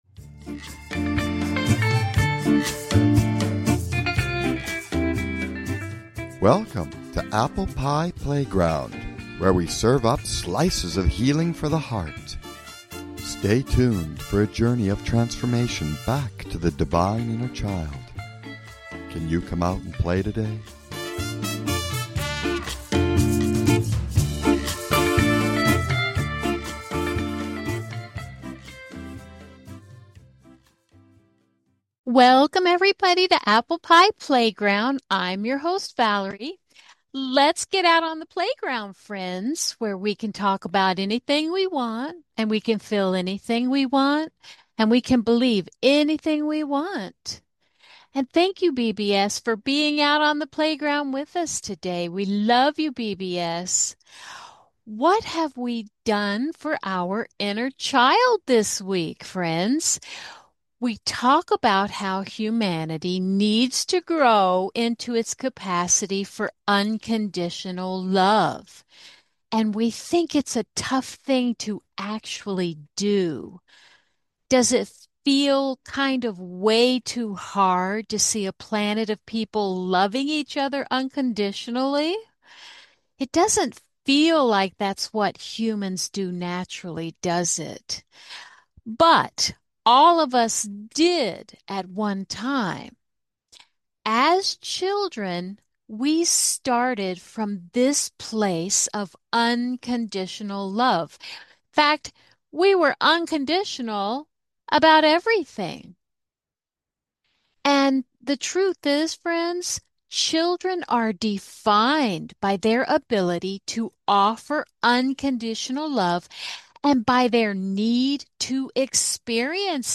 Talk Show Episode, Audio Podcast, Apple Pie Playground and What's Unconditional Love Got to Do with it? on , show guests , about What's Unconditional Love Got to Do with it,Sovereignty is the spirit's highest order,Reconnecting Through the Geometry of the Heart,Overcoming the Amnesia of the Matrix,Spiritual Sovereignty and Natural Law,Neurological Impact of Unconditional Love,The Biological Necessity of Love and Brain Development,The Sovereignty of Unconditional Love,A Journey of Heart Healing,Inner Child Reclamation, categorized as Education,Entertainment,Games & Hobbies,Health & Lifestyle,Kids & Family,Philosophy,Self Help,Society and Culture,Spiritual